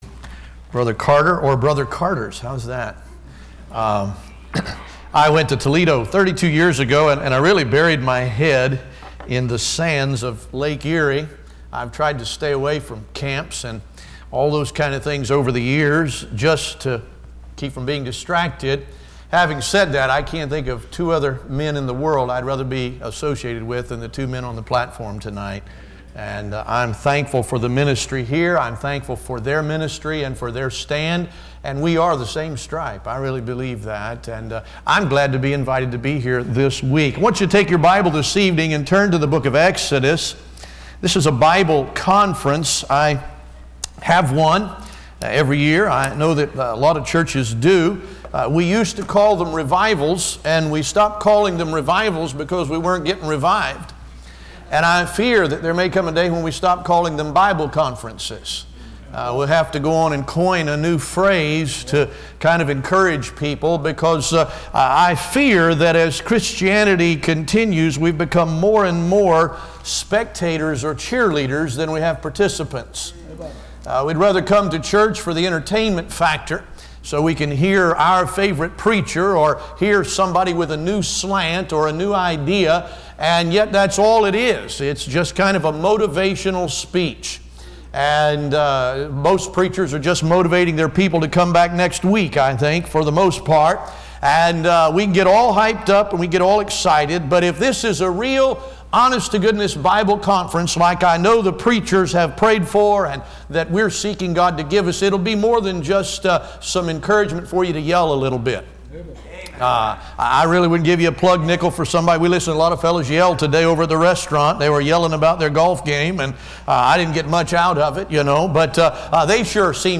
Listen to Message
Service Type: Bible Conference